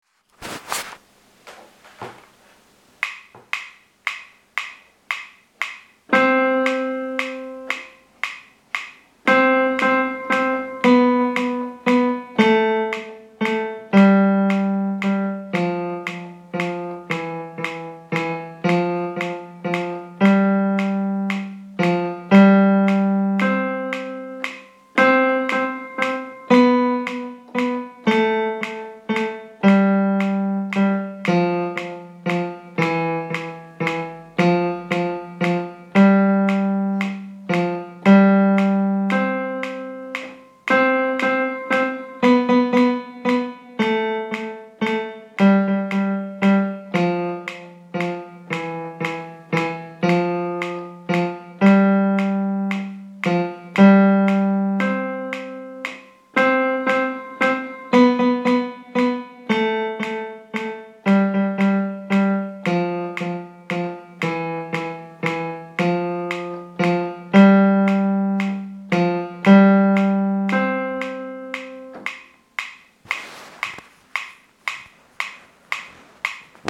švédská barokní píseň - Pam, pam, pam